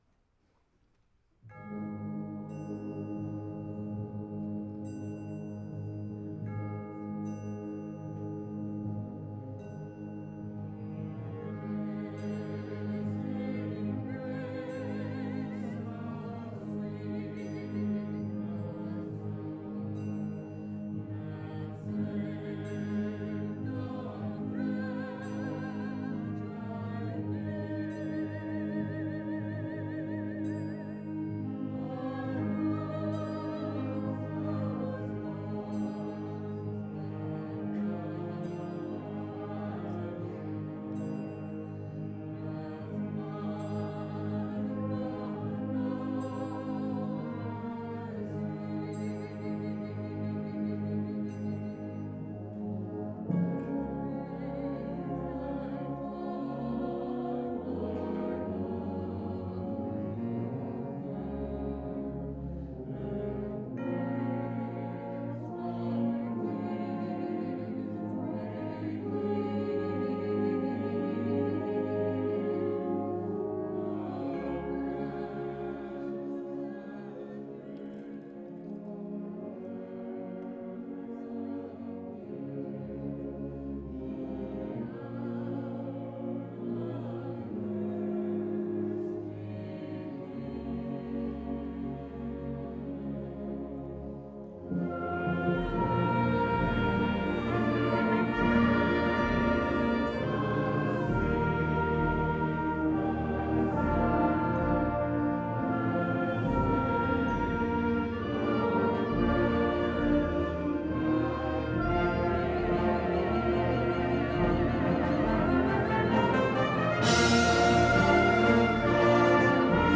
2014 Summer Concert